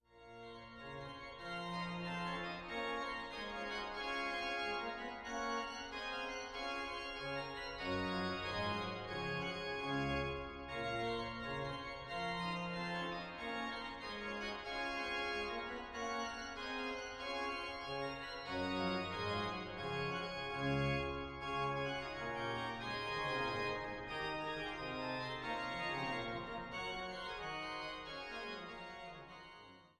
Einweihungskonzertes vom 03.11.2000